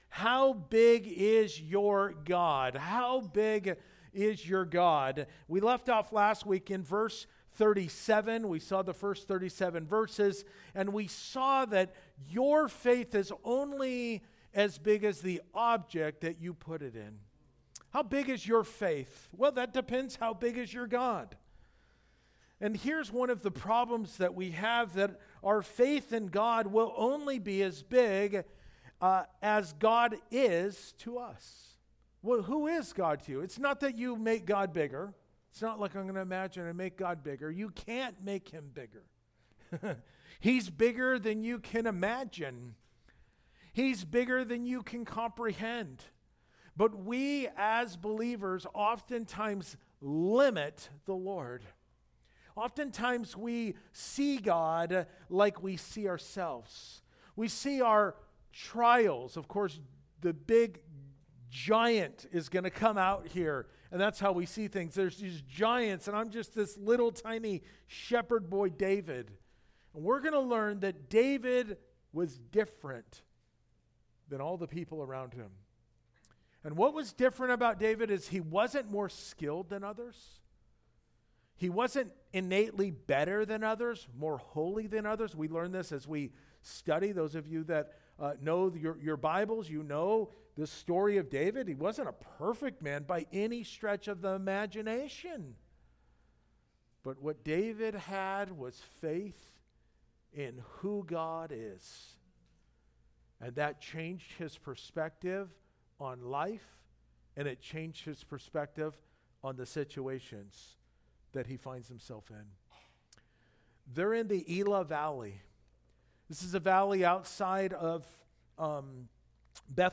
How+Big+is+your+God+pt+2+2nd+service.mp3